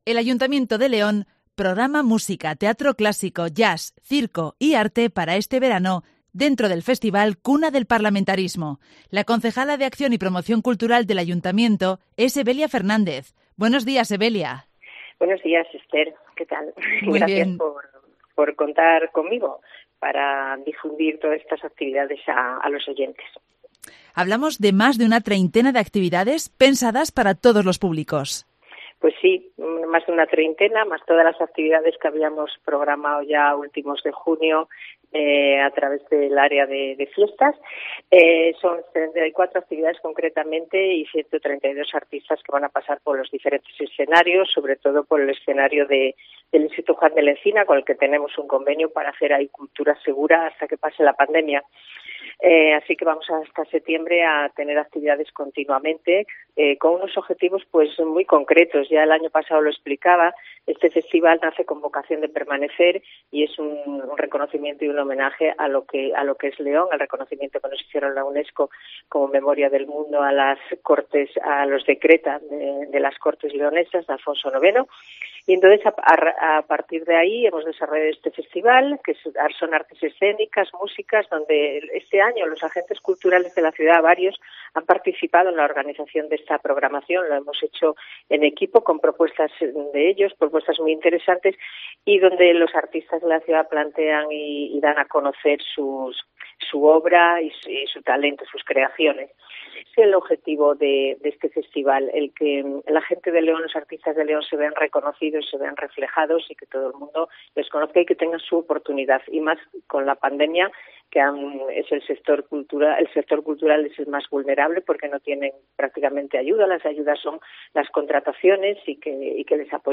AUDIO: La concejala de Acción y Promoción Cultural del Ayuntamiento de León, Evelia Fernández, ha intervenido en el programa local de Cope León para...